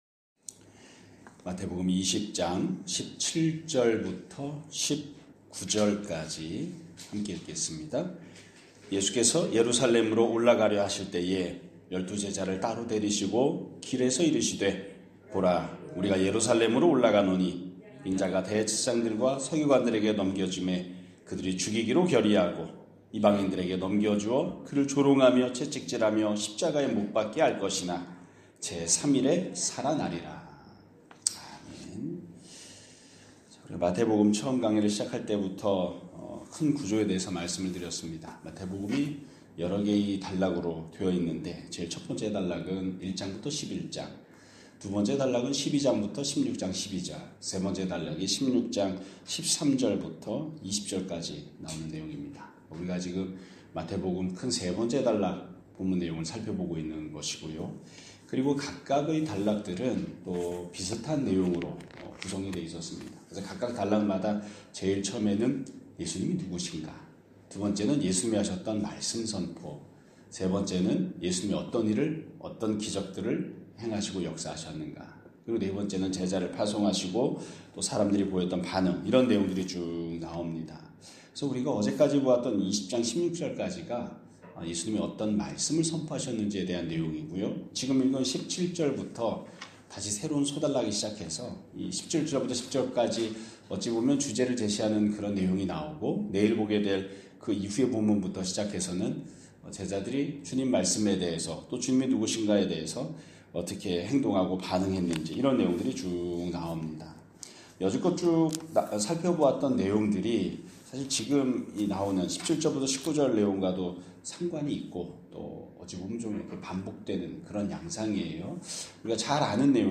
2026년 1월 15일 (목요일) <아침예배> 설교입니다.